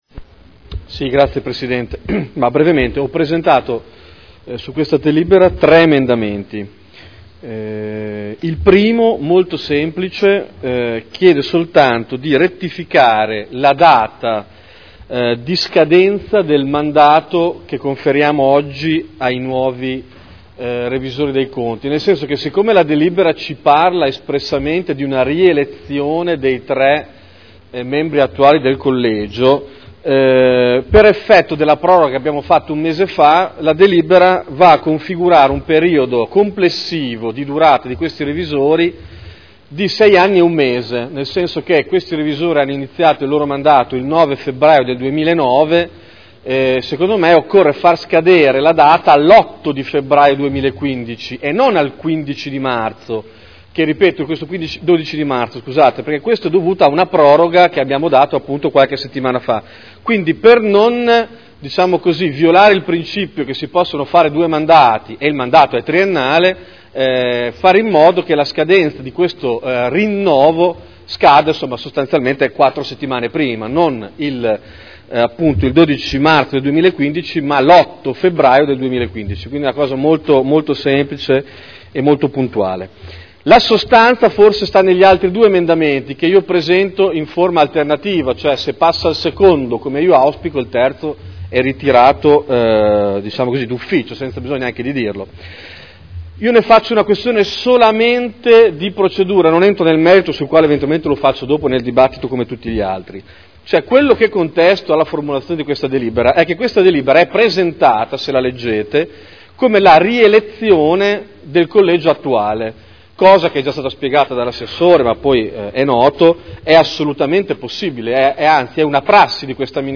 Seduta del 12/03/2012. Rielezione del Collegio dei Revisori dei conti per il triennio 2012 - 2014 (Conferenza Capigruppo del 23, 30 gennaio e 5 marzo 2012). Presenta tre emendamenti.